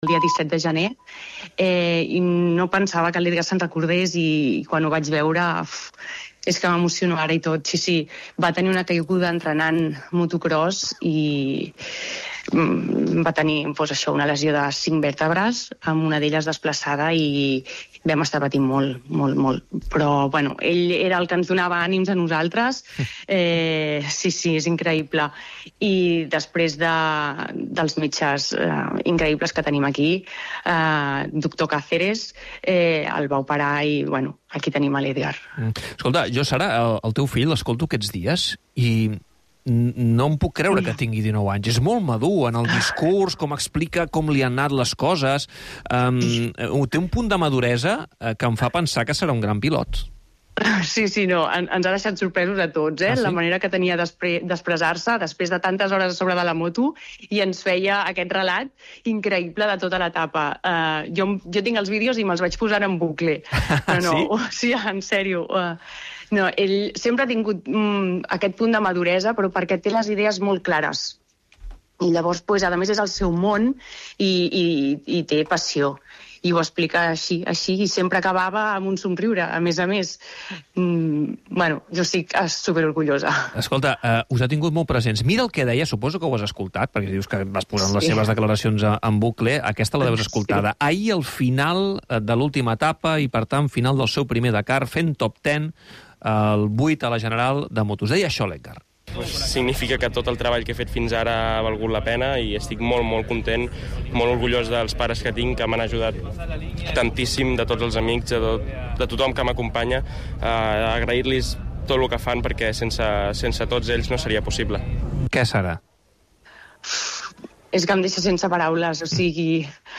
El programa esportiu del cap de setmana per estar al dia de totes les competicions. 19 temporades i ms de 2.000 programes vivint l'esport en directe.